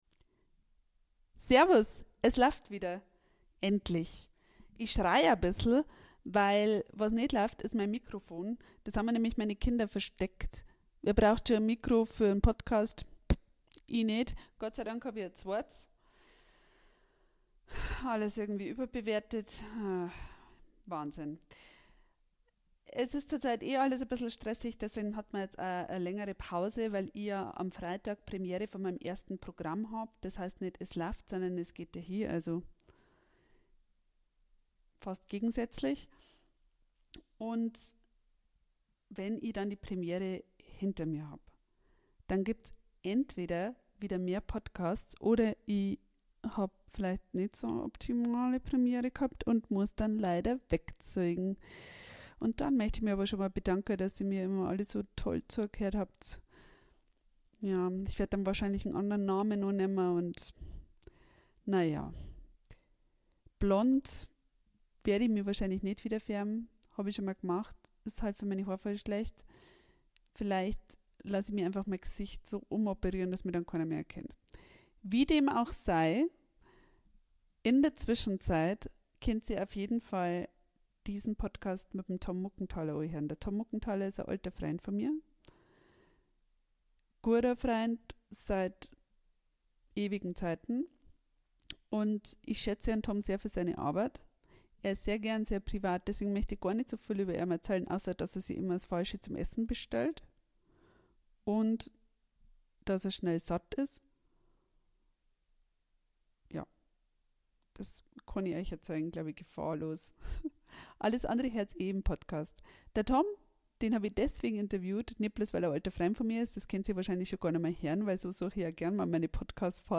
Gespräch zu einem Kulturpreis ~ EsLafft Podcast
Ein Gespräch unter Freunden.